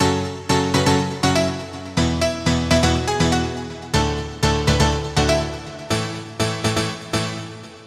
斯金多钢琴
Tag: 122 bpm House Loops Piano Loops 1.32 MB wav Key : Unknown